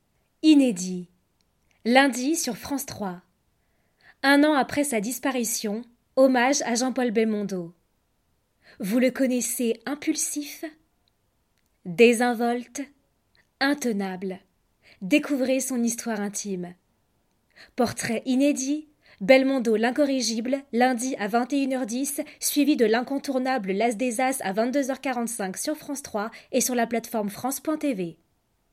Maquette Soirée Belmondo France 3 (bande annonce)